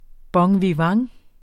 Udtale [ bʌŋviˈvɑŋ ]